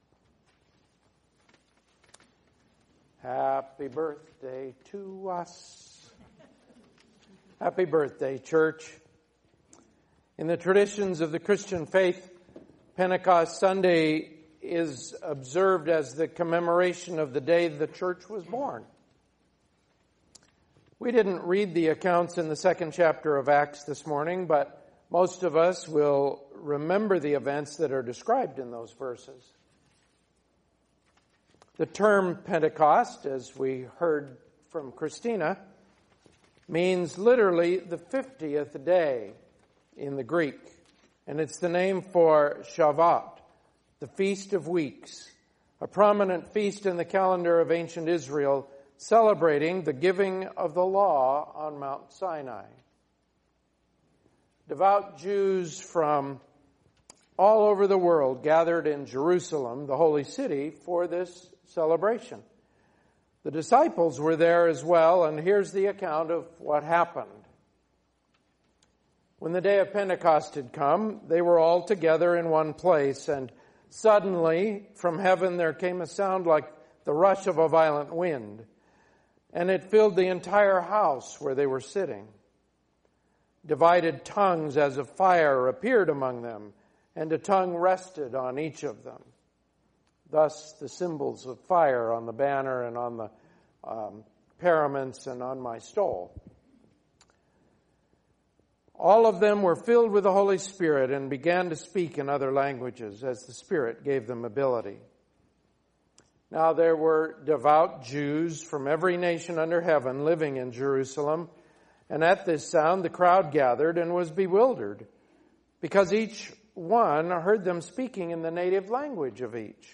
Pentecost Sermon